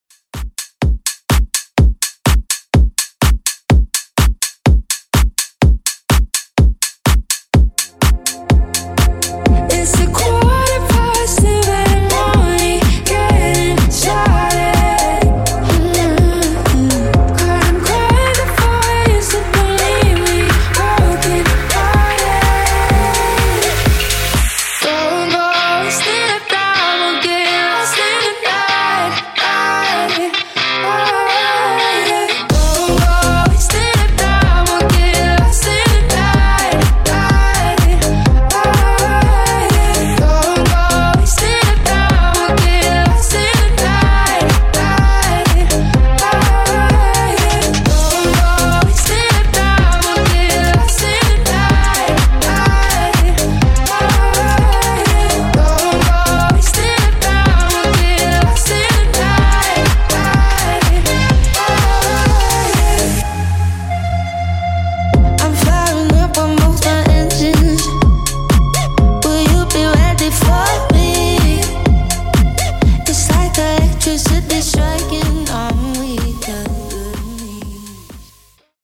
Club Extended Mix)Date Added